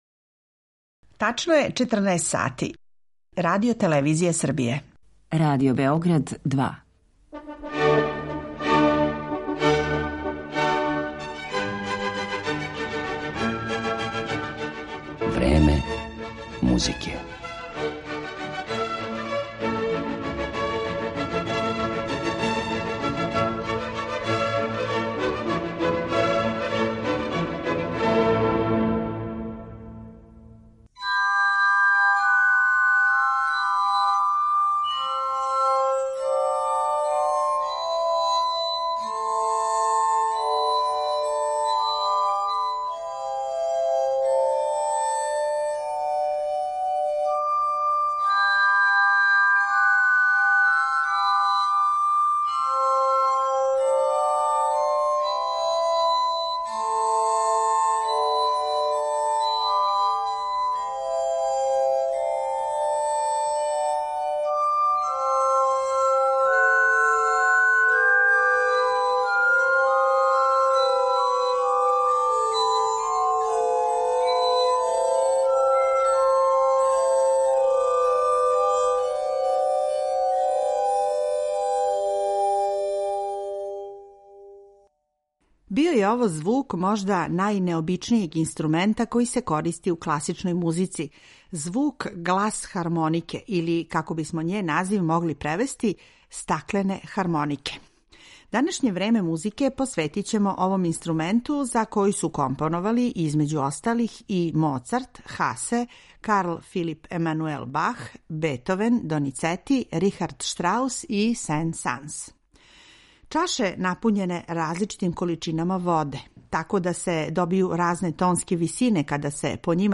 Звук гласхармонике
У емисији Време музике слушаћете звук можда најнеобичнијег инструмента који се користи у класичној музици -- звук гласхармонике или, како бисмо њен назив могли превести -- стаклене хармонике.